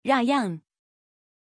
Aussprache von Rayyan
pronunciation-rayyan-zh.mp3